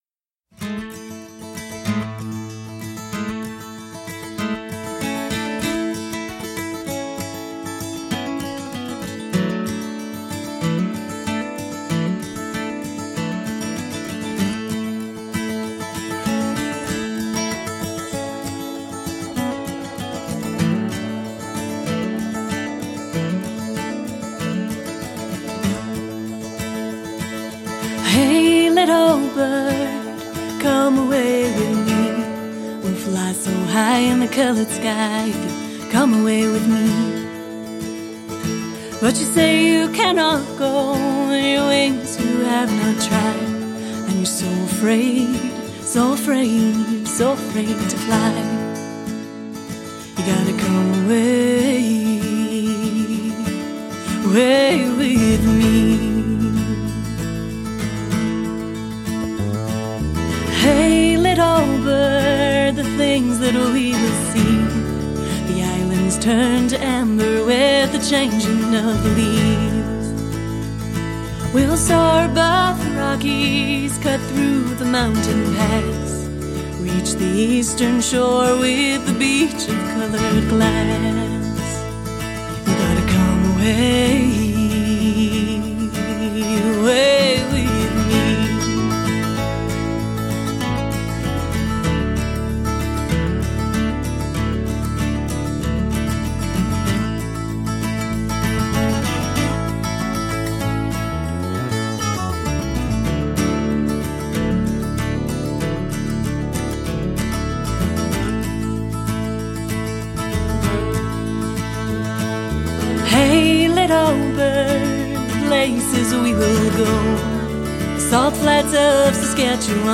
a folk Canadiana duo from Winnipeg.
guitar